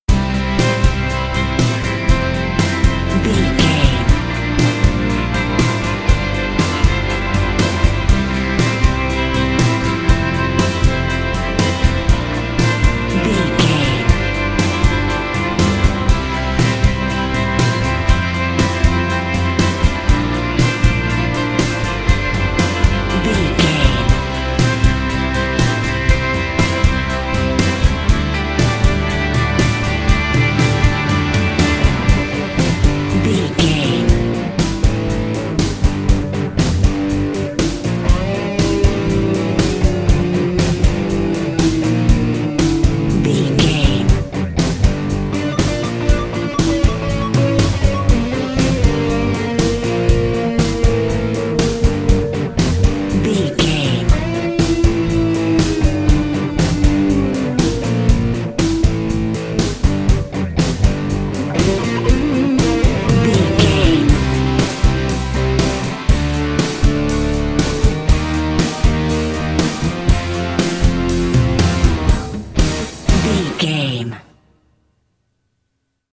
Aeolian/Minor
drums
electric guitar
Sports Rock
hard rock
lead guitar
bass
aggressive
energetic
intense
powerful
nu metal
alternative metal